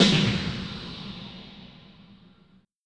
Snare 30.wav